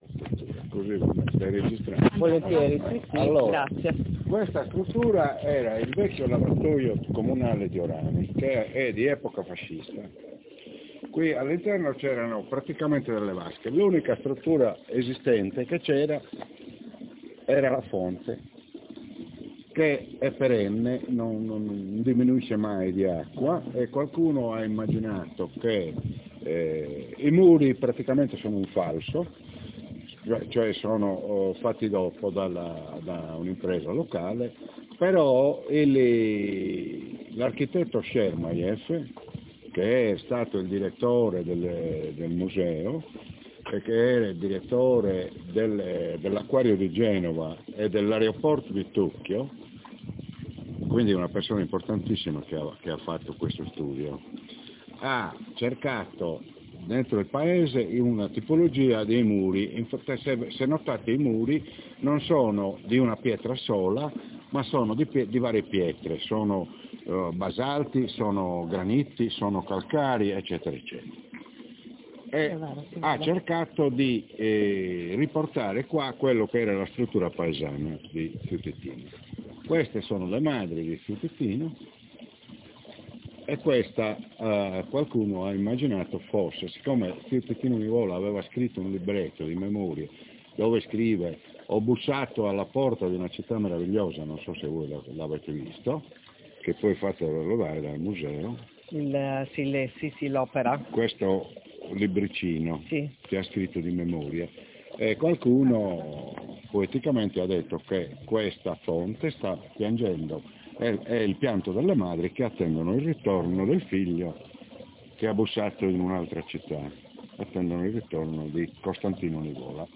Intervista
Luogo dell'intervista Cimitero di Orani